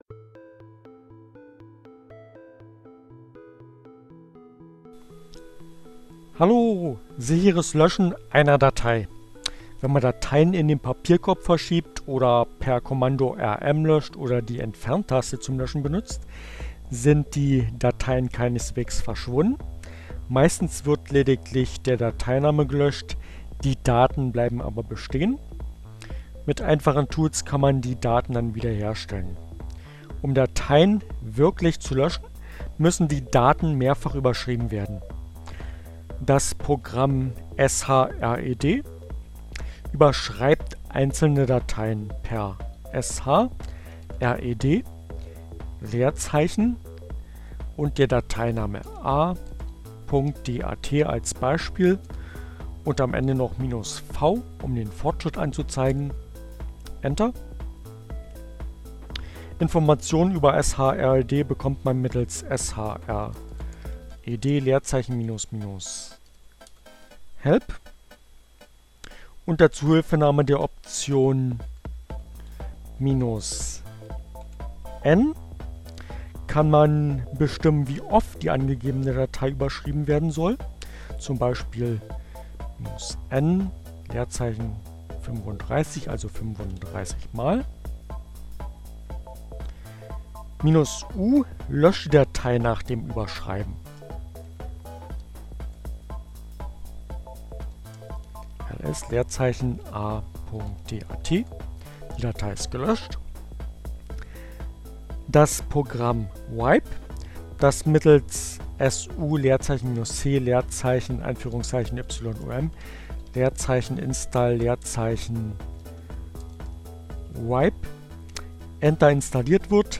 Tags: CC by-sa, Fedora, Gnome, Linux, Neueinsteiger, Ogg Theora, ohne Musik, screencast, gnome3, shred, wipe